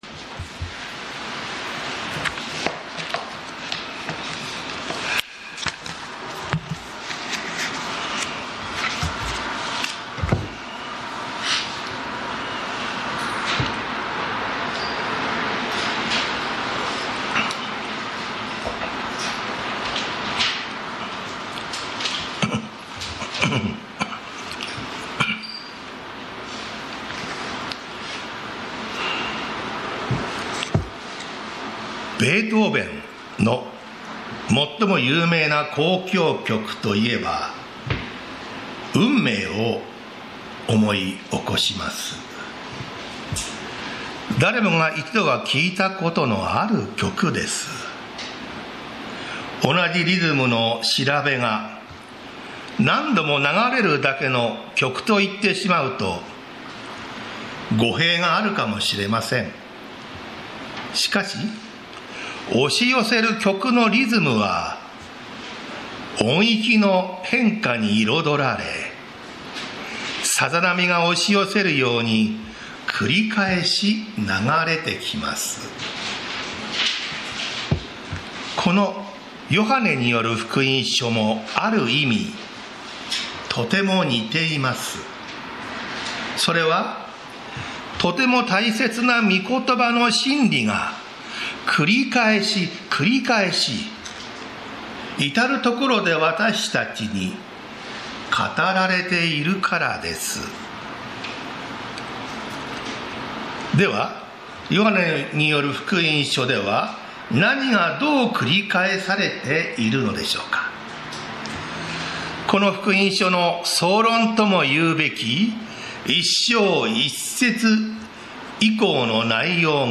栃木県鹿沼市 宇都宮教会
日曜 朝の礼拝